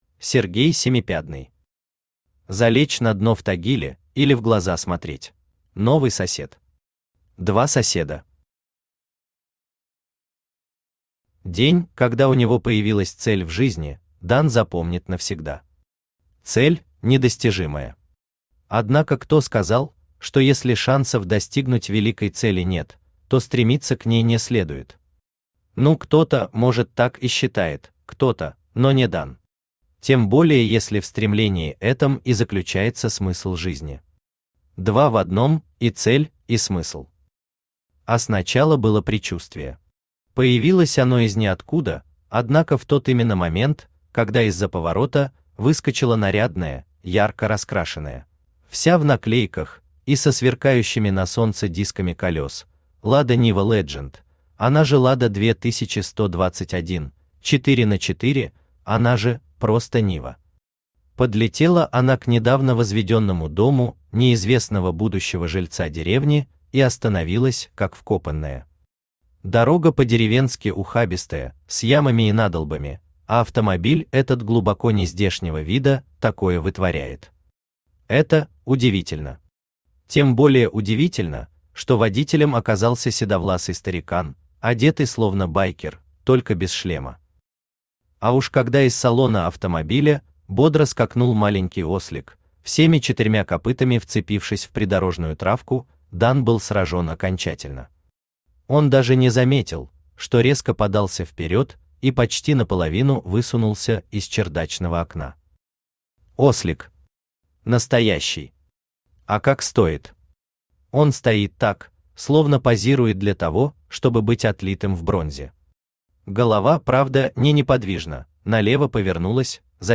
Аудиокнига Залечь на дно в Тагиле, или «В глаза смотреть!»
Автор Сергей Семипядный Читает аудиокнигу Авточтец ЛитРес.